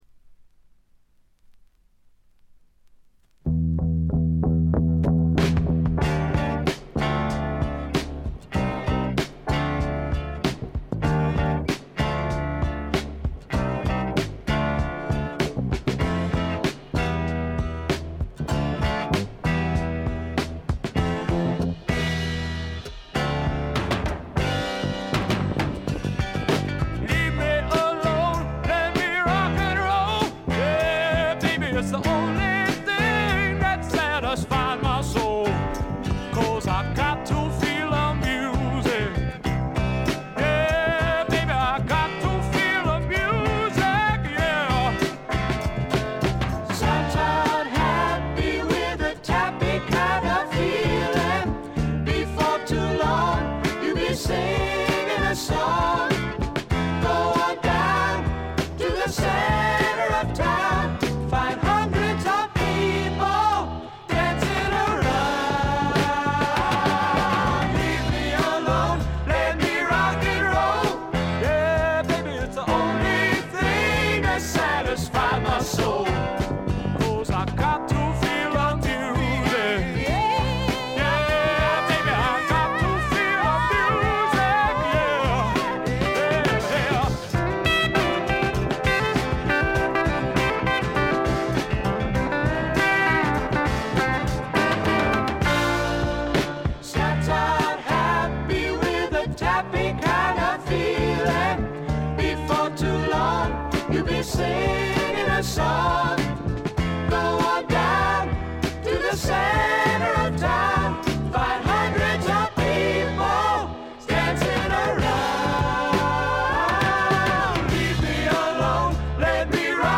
ホーム > レコード：英国 SSW / フォークロック
軽微なチリプチ少々。
静と動の対比も見事でフォークロック好きにとってはこたえられない作品に仕上がっています！
試聴曲は現品からの取り込み音源です。